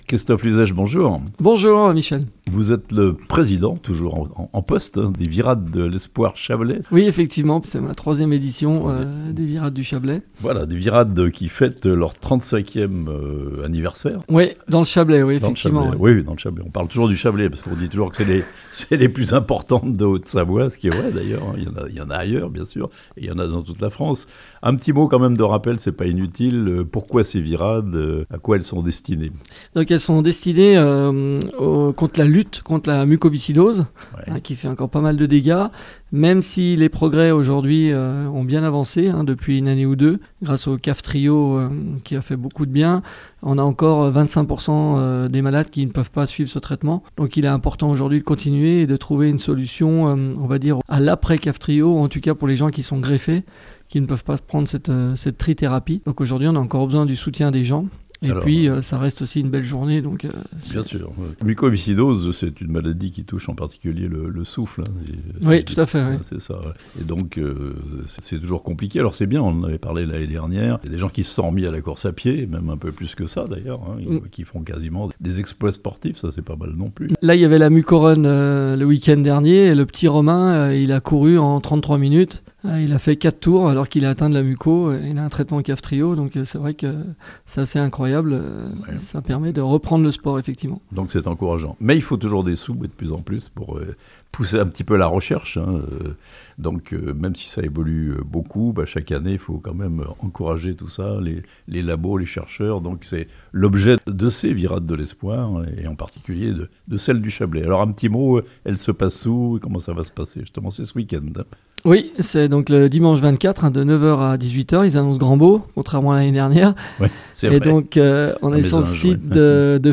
Fessy, Lully et Brenthonne : 3 communes du Chablais s'associent pour organiser La Virade de l'Espoir 2023 (interview)